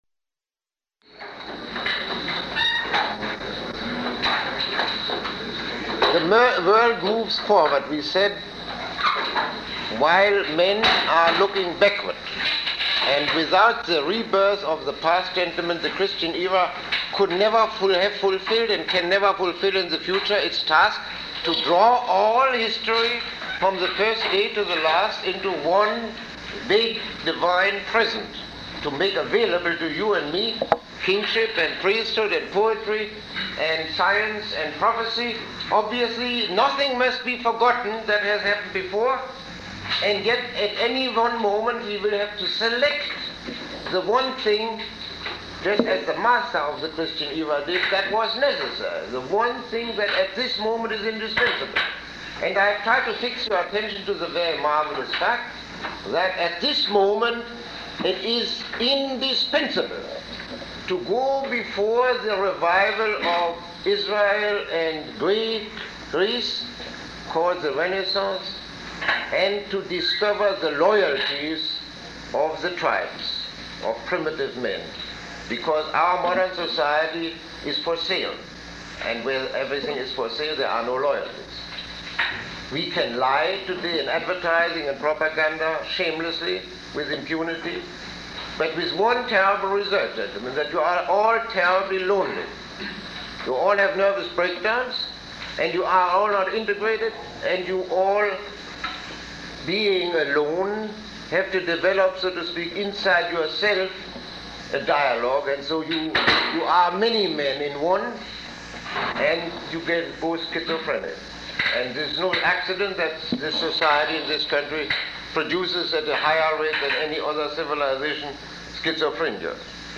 Lecture 29